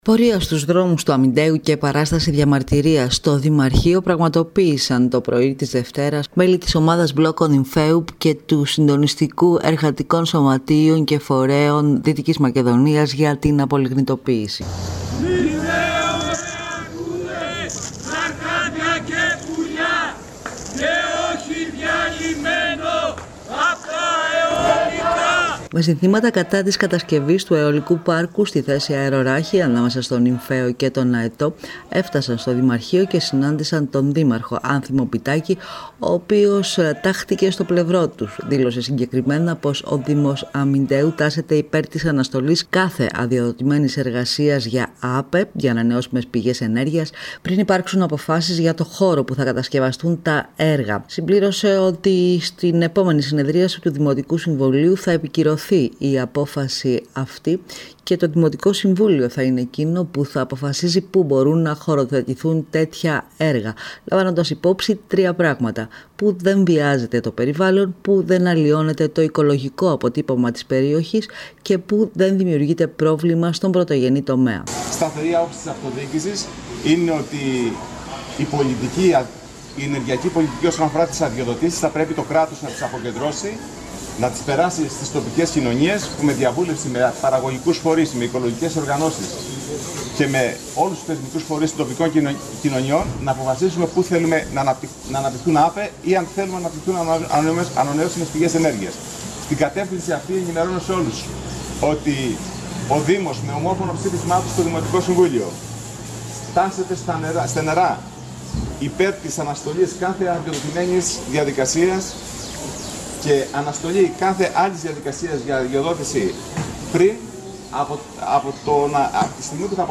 Τη δέσμευση του ότι θα σταθεί στο πλευρό των κατοίκων, που αντιτίθενται στην κατασκευή αιολικού πάρκου στη θέση Αερροράχη, εξέφρασε ο Δήμαρχος Αμυνταίου Άνθιμος Μπιτάκης, σε μέλη της ομάδας “Μπλόκο Νυμφαίου” που πραγματοποίησαν πορεία στους δρόμους του Αμυνταίου και παράσταση διαμαρτυρίας στο Δημαρχείο, το πρωί της Δευτέρας 28 Ιουνίου.